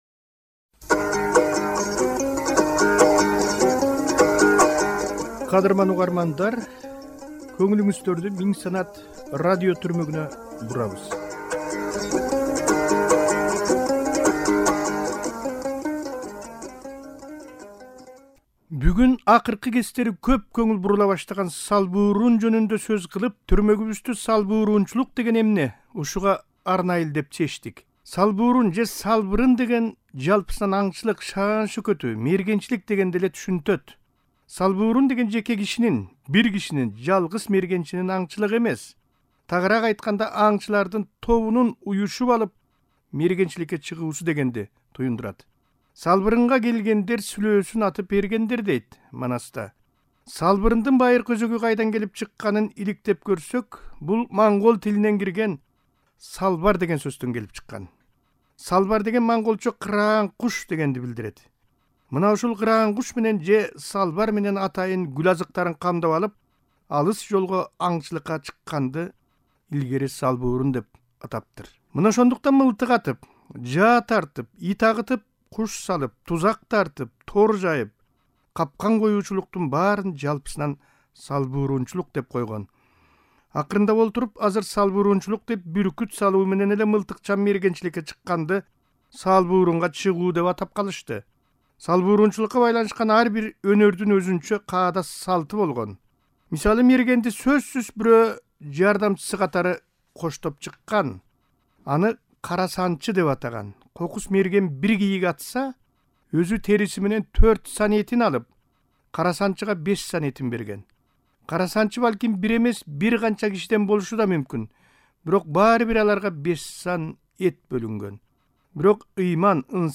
"Миңсанаттын" бул санында салбурун өнөрүн жайылтуу жана мелдештер тууралуу маекти уга аласыздар.